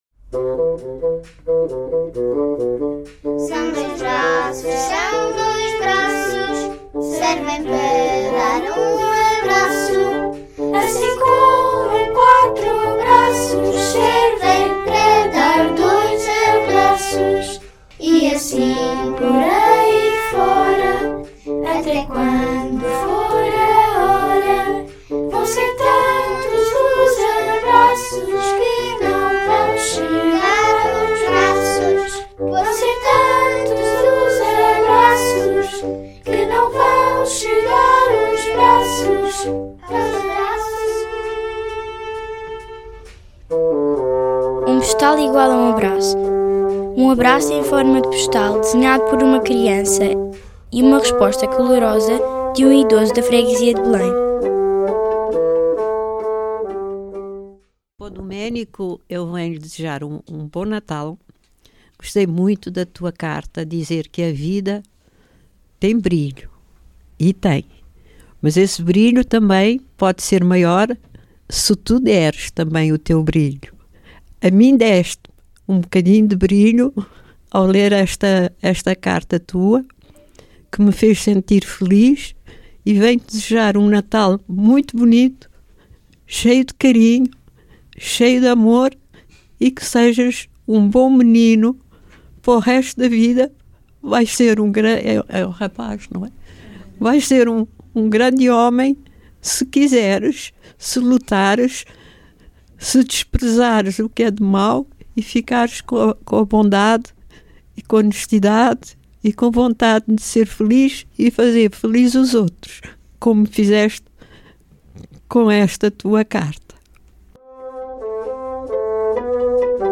Um abraço em forma de postal desenhado por uma criança e uma resposta calorosa de um idoso da freguesia de Belém.
Oiça aqui as respostas dos idosos aos postais enviados pelos alunos.